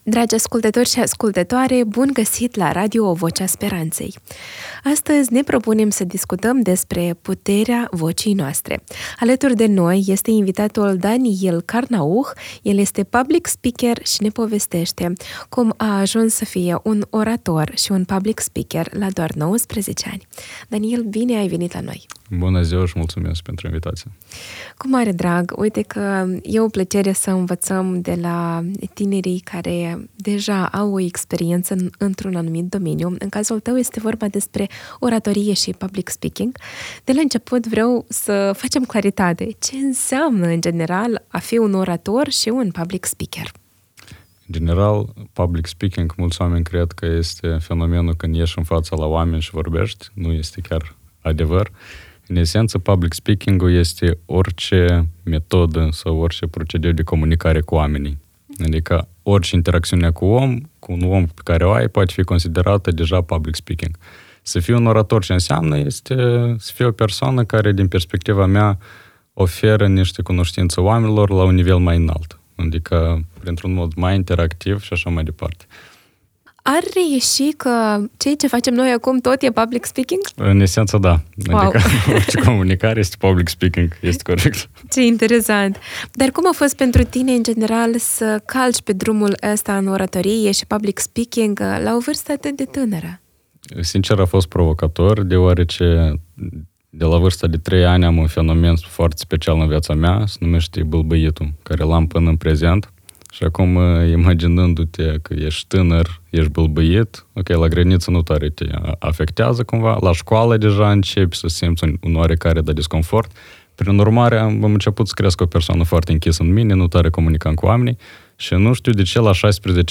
Abordăm gestionarea emoțiilor, importanța limbajului non-verbal, greșelile frecvente ale începătorilor și beneficiile reale ale dezvoltării abilităților de public speaking. Un dialog util pentru tineri care vor să-și dezvolte vocea, claritatea și impactul personal.